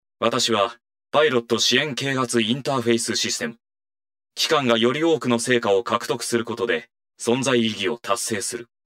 but they also recently added one for the Striker, voiced by Ayumi Fujimura: